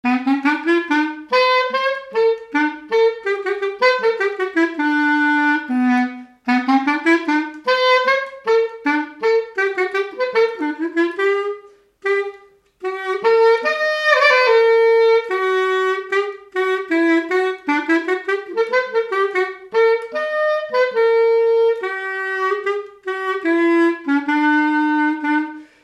Résumé instrumental
circonstance : fiançaille, noce
Pièce musicale inédite